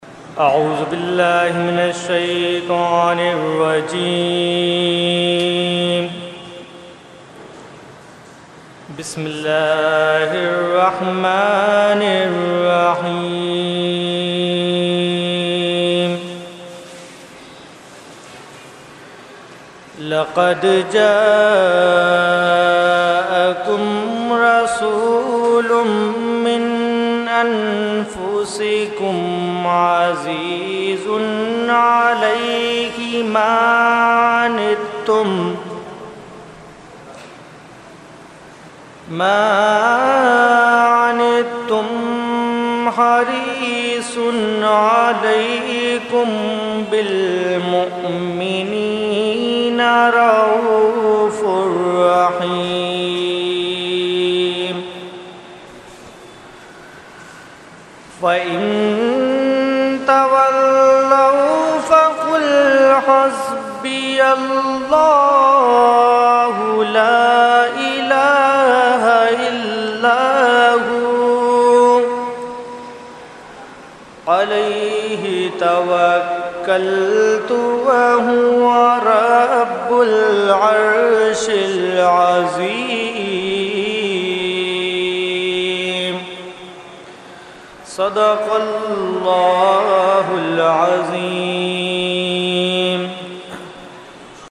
Category : Qirat | Language : ArabicEvent : Dars Quran Farooqi Masjid 8 June 2012